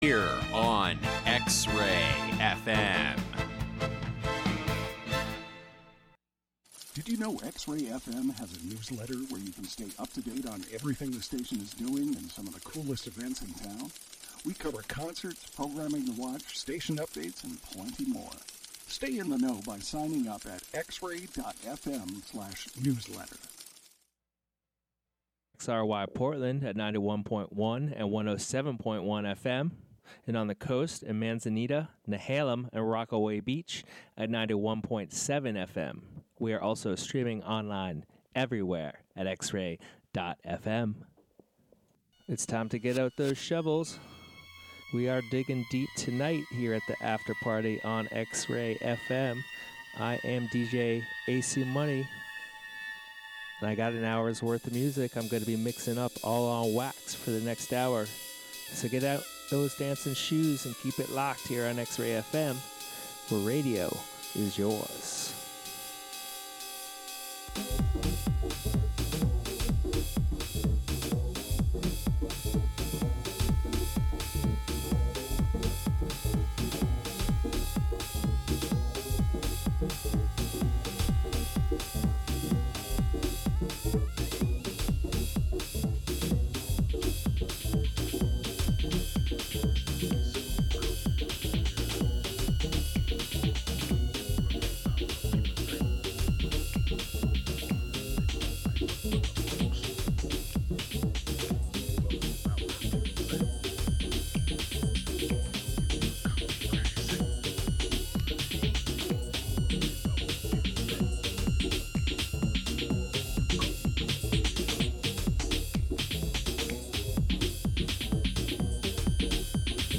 One hour of soulful underground sounds mixed up